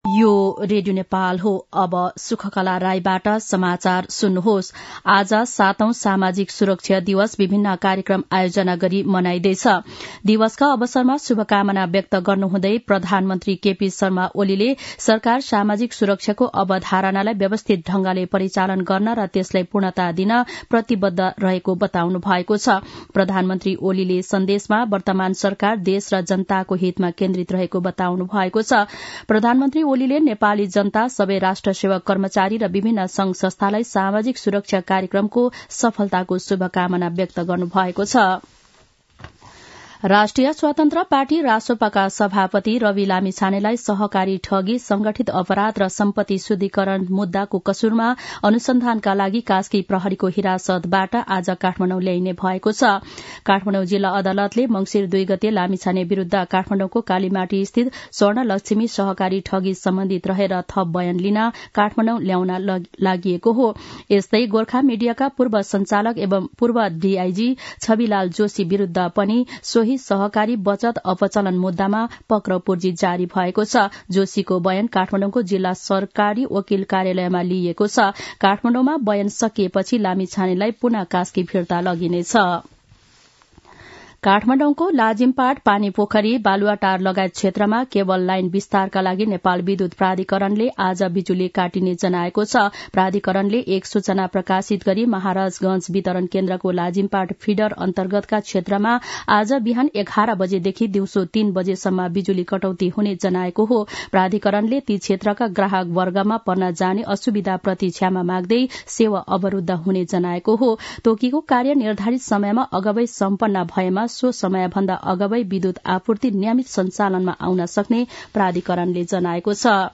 दिउँसो १ बजेको नेपाली समाचार : १२ मंसिर , २०८१
1-pm-nepali-news-1-8.mp3